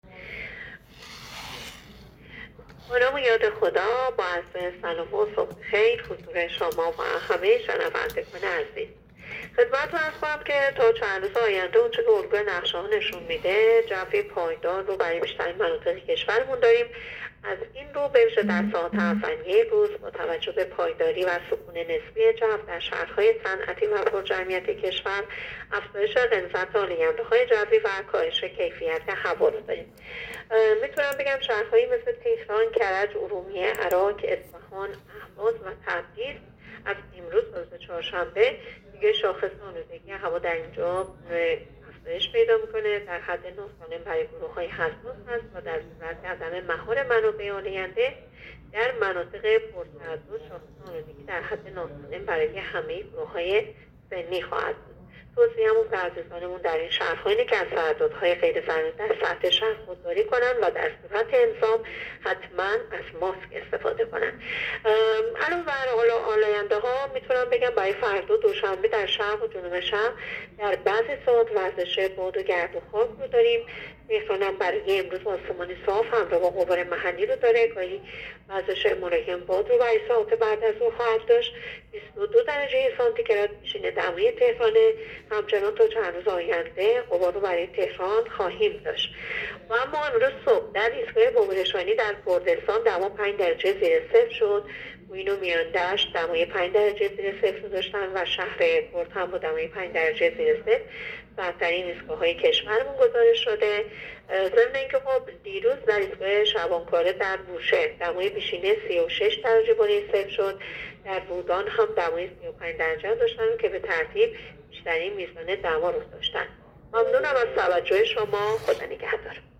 گزارش رادیو اینترنتی پایگاه‌ خبری از آخرین وضعیت آب‌وهوای ۱۸ آبان؛